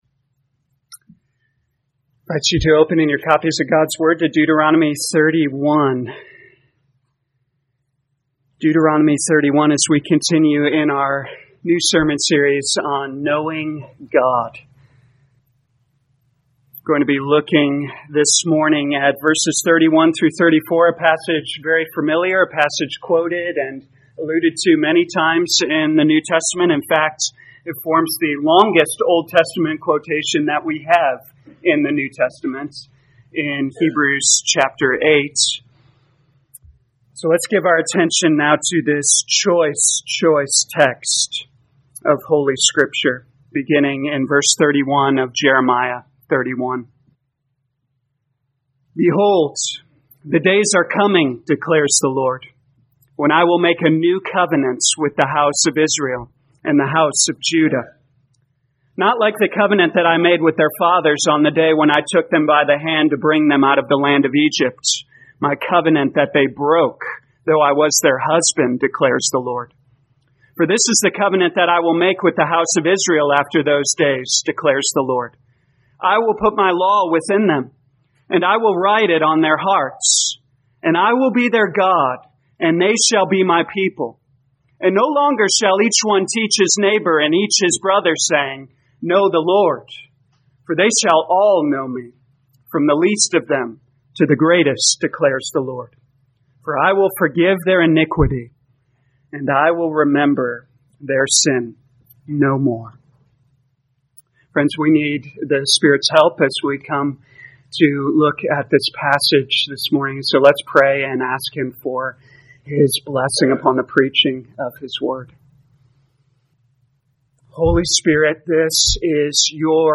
2022 Jeremiah Knowing God Morning Service Download